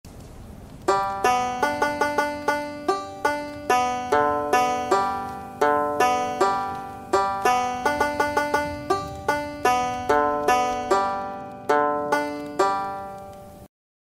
Пример мелодии сыгранной на Банджо